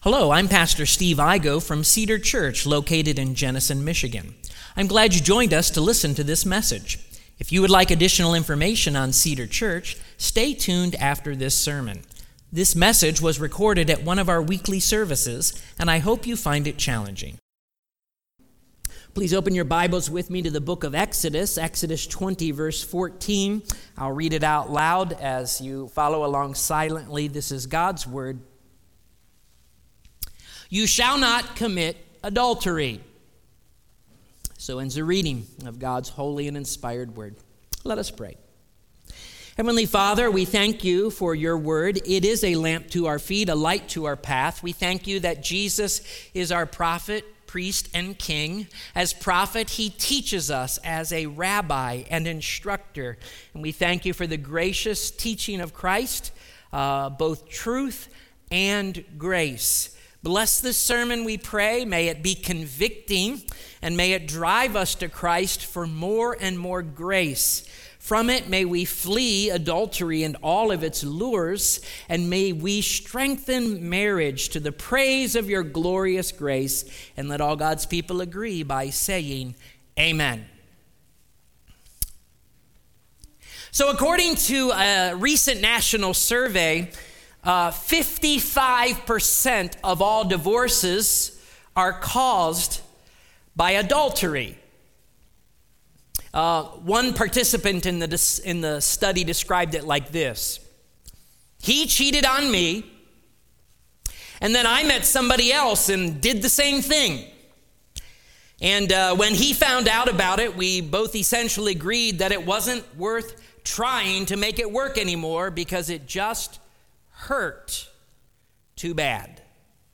Sermons | Cedar Church
Current Sermon